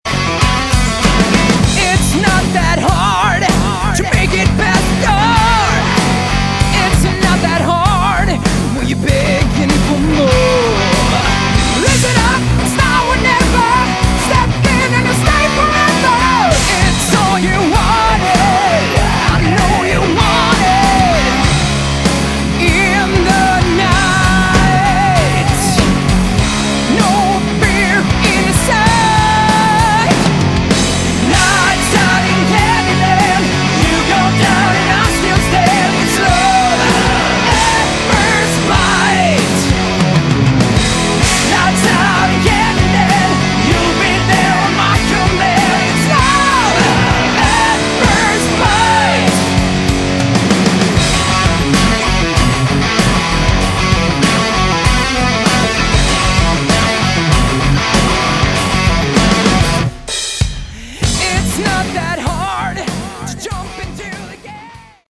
Category: Hard Rock
Vocals
Guitars
Drums
Bass